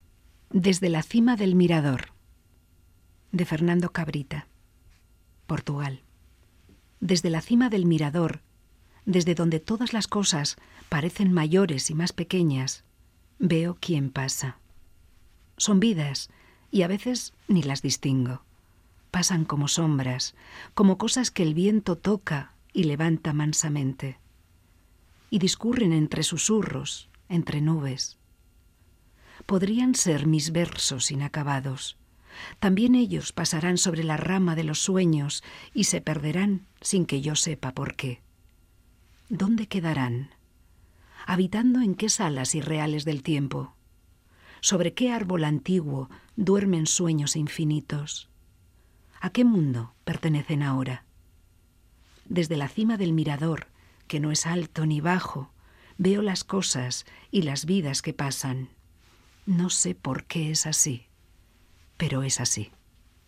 Disfruta con esta poesía, perteneciente al festival 'Poetas en Mayo', que se lleva a cabo en Vitoria-Gasteiz durante el mes de mayo de 2018. Poema del festival Poetas en Mayo, de cultura de Gasteiz, en Radio Vitoria.